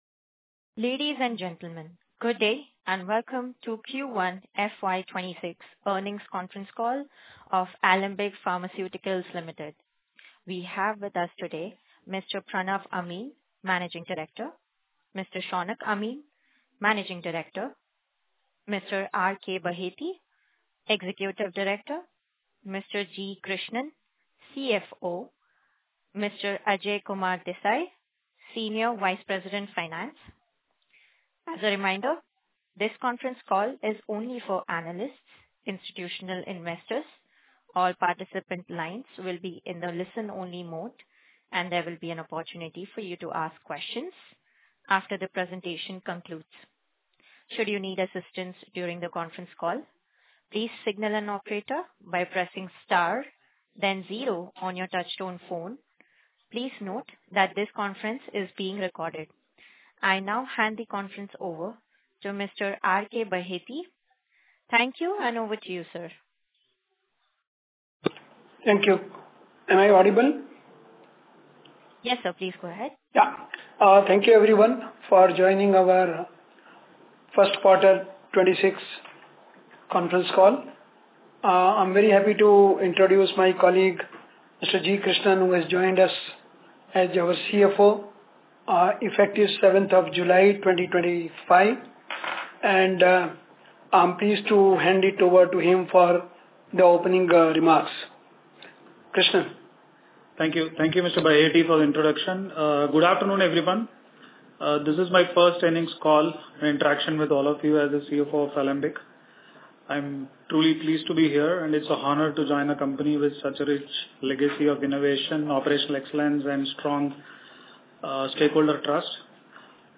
Earning-Conference-Call-5th-August-2025.mp3